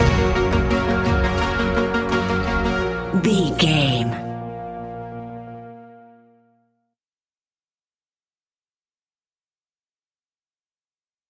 In-crescendo
Thriller
Aeolian/Minor
scary
tension
ominous
dark
eerie
horror music
Horror Pads
horror piano
Horror Synths